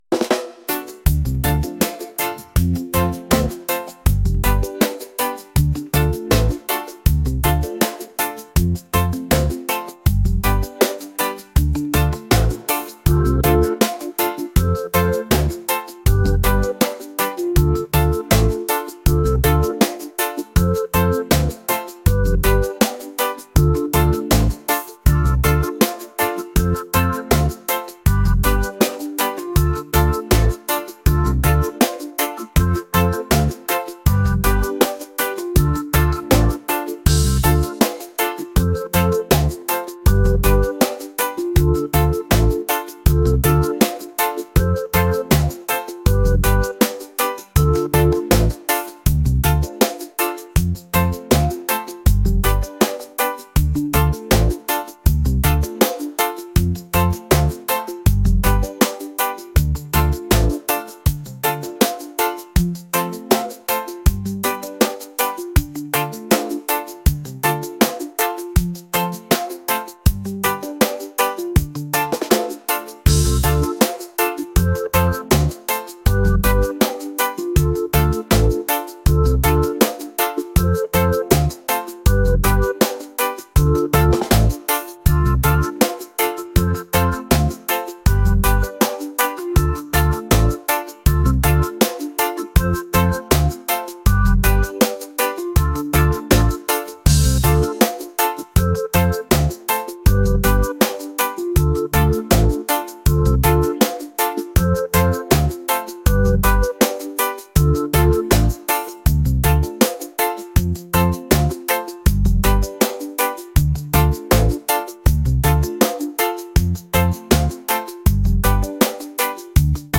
reggae | soul & rnb